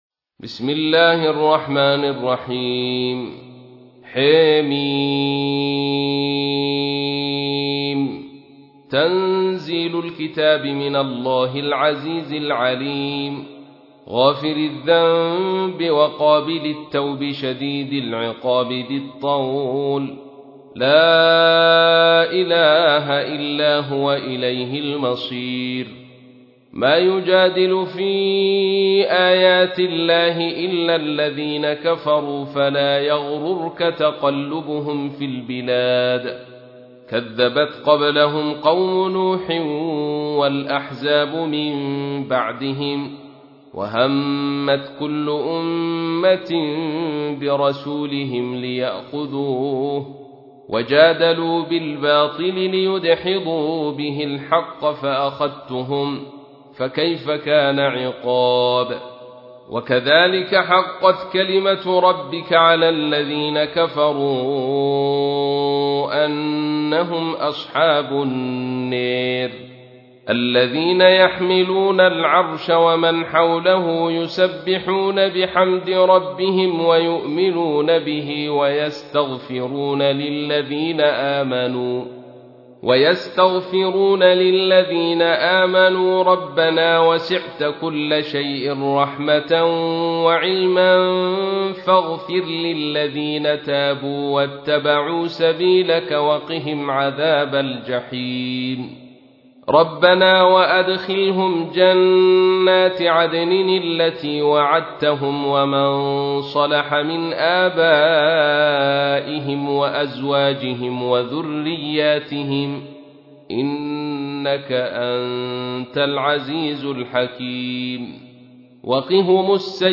تحميل : 40. سورة غافر / القارئ عبد الرشيد صوفي / القرآن الكريم / موقع يا حسين